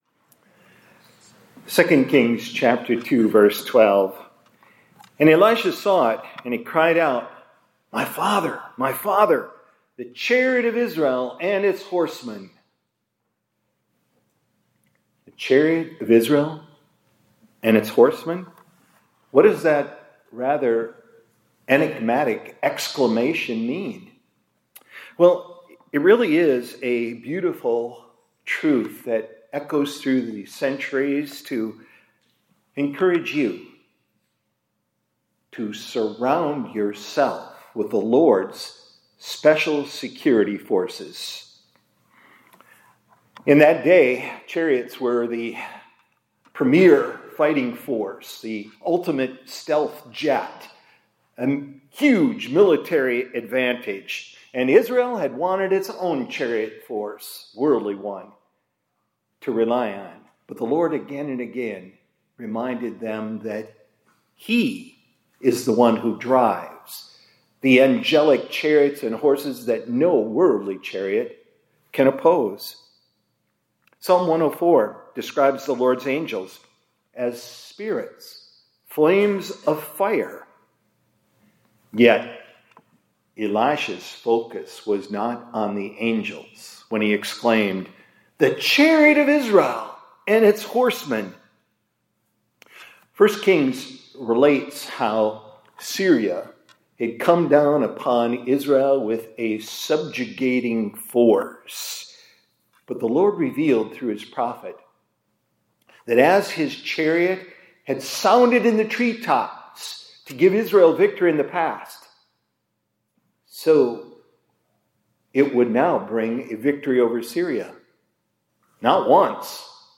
2025-10-09 ILC Chapel — Surround Yourself With the Lord’s…